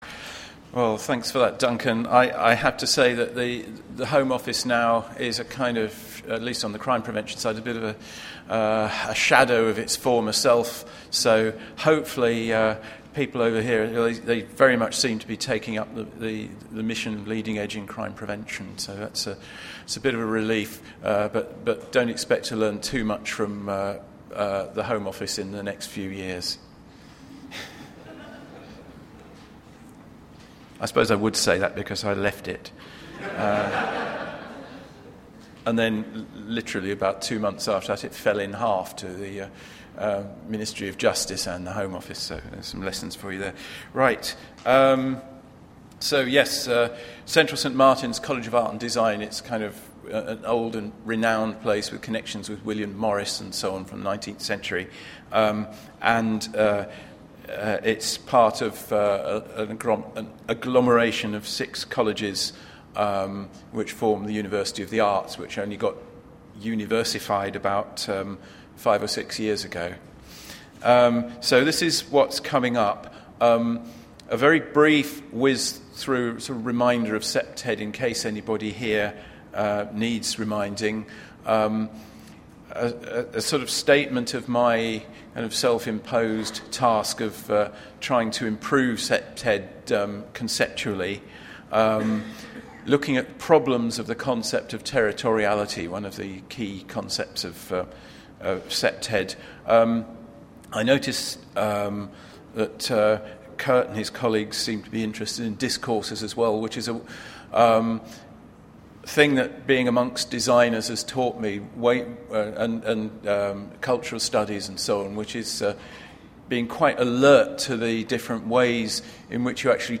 ‘ Teasing apart Territoriality… and reassembling it as a useful concept for practice, research and theory’, Seminar on ‘Critical Perspectives of CPTED ‘, Institute of Criminology, University of Sydney.